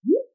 SFX_ItemPickUp_03.wav